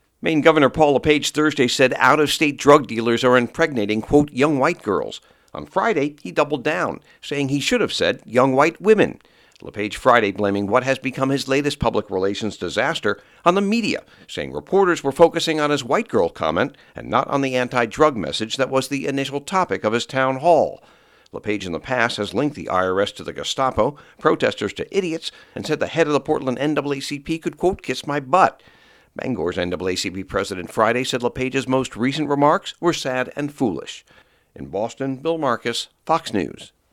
(BOSTON) JAN 8 – THE GOVERNOR OF MAINE BLAMING A BLOGGER AND THE MEDIA FOR BEING OUT TO GET HIM FOR PUBLICIZING REMARKS HE MADE THURSDAY NIGHT AS RACIST. FOX NEWS RADIO’S